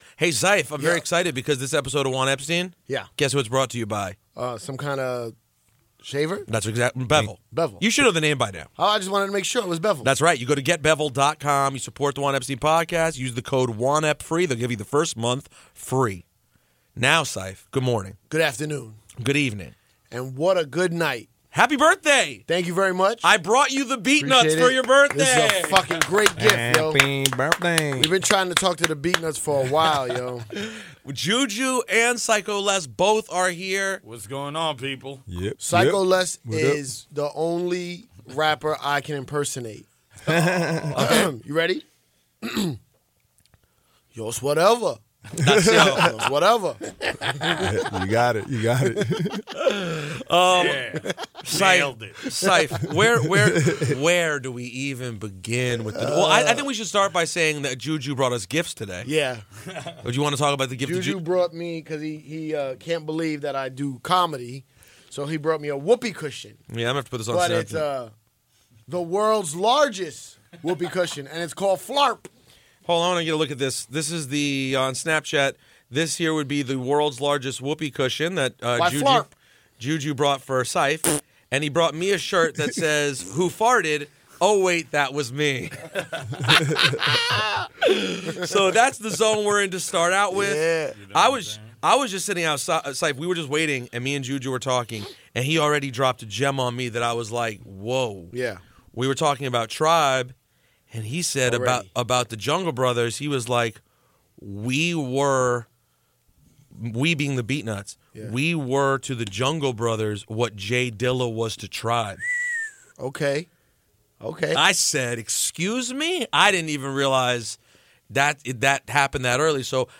Informative, passionate, awesome, slightly drunk.